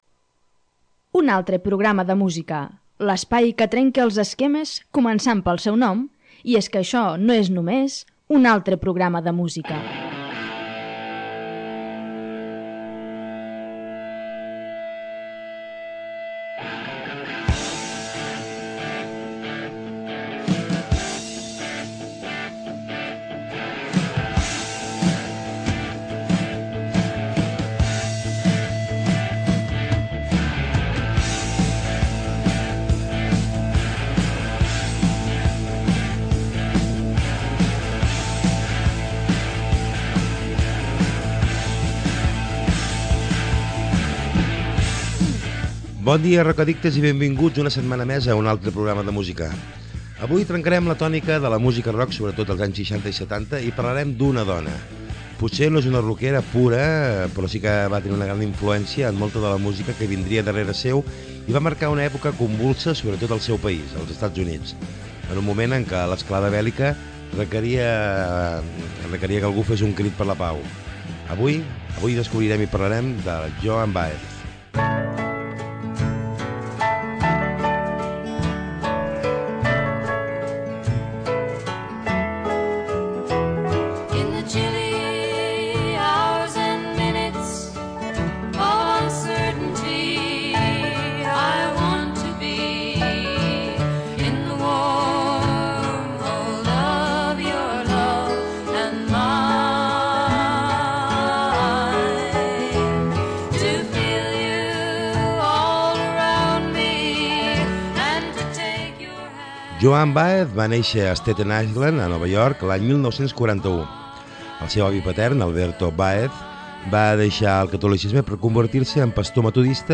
Un dimecres més el rock ocupa la sintonia de l’Espluga FM Ràdio.